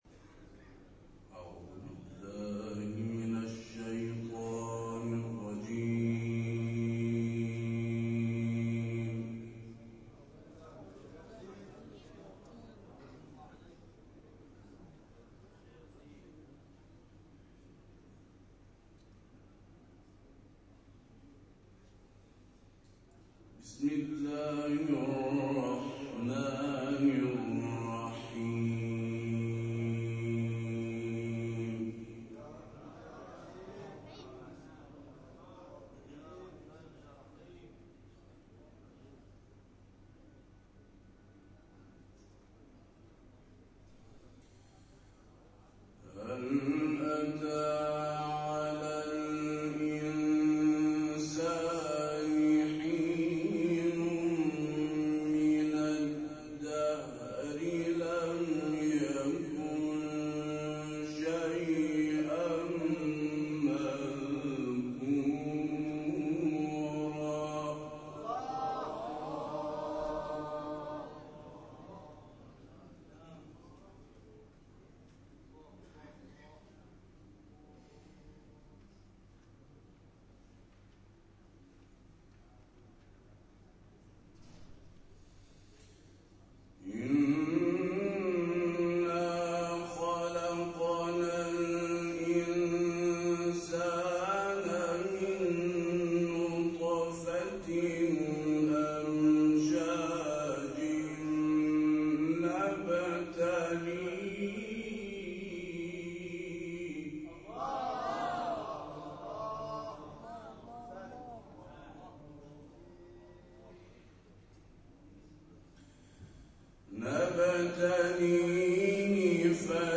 جدیدترین تلاوت